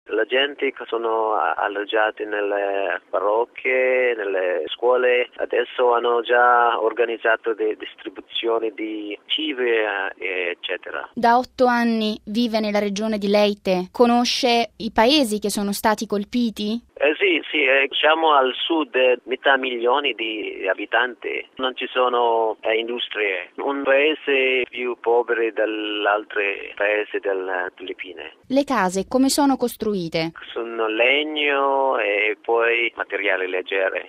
Anche la Chiesa locale si è attivata nell’opera di soccorso e nell’aiuto ai sopravvissuti come ci spiega dalle Filippine il vescovo di Maasin, Precioso Cantillas: RealAudio